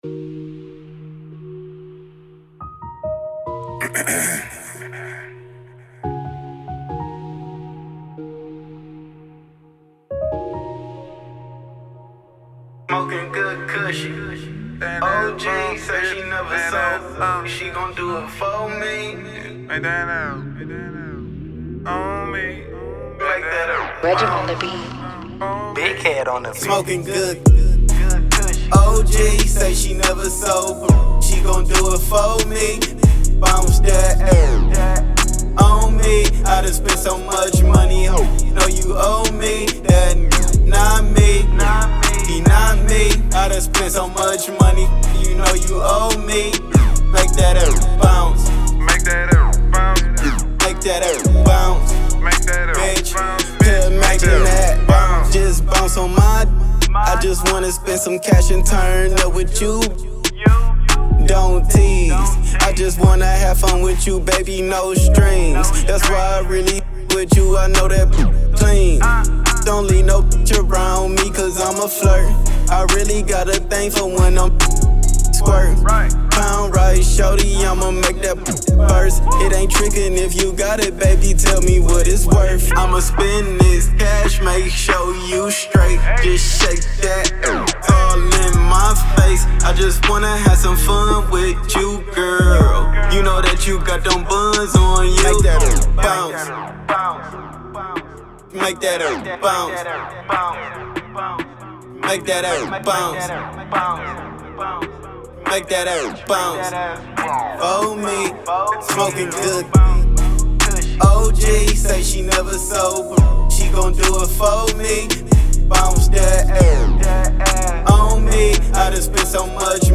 strip club anthem